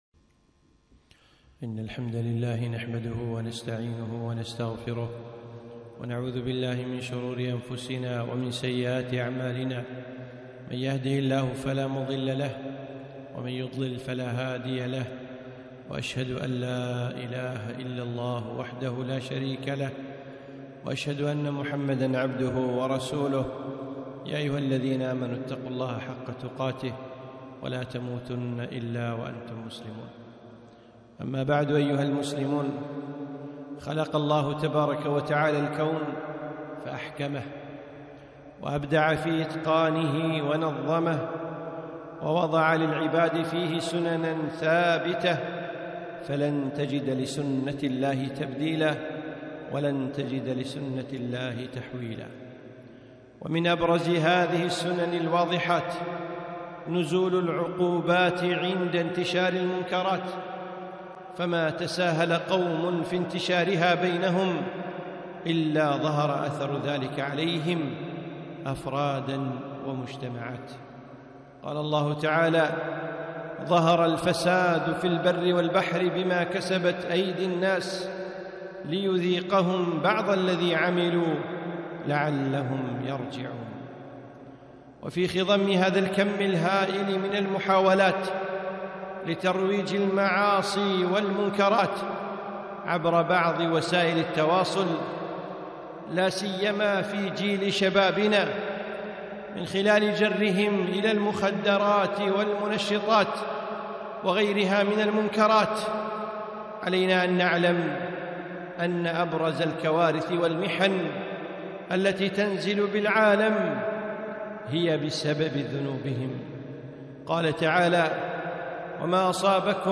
خطبة - الحذر من المحرمات